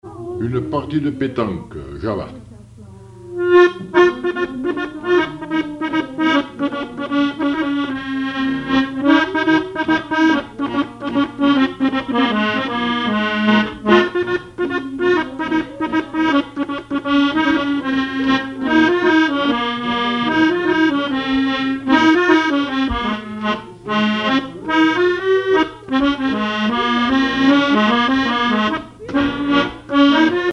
accordéon(s), accordéoniste
danse : java
Répertoire à l'accordéon chromatique
Pièce musicale inédite